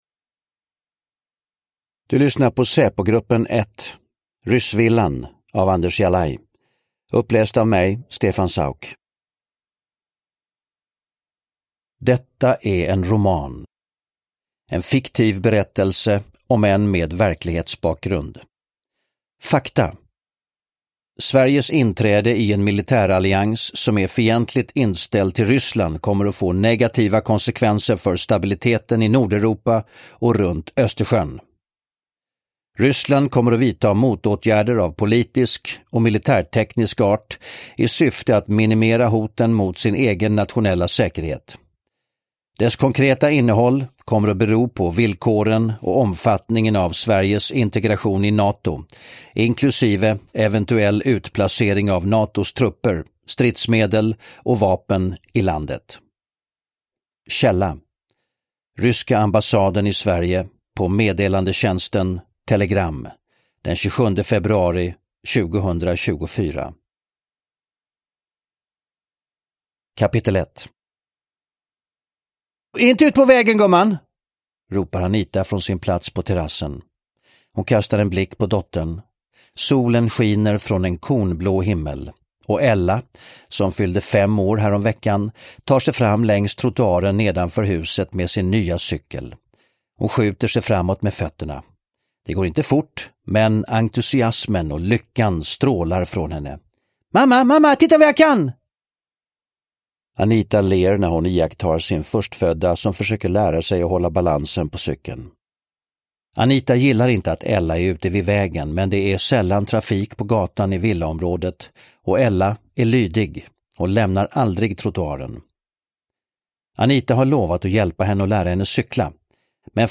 Uppläsare: Stefan Sauk
Ljudbok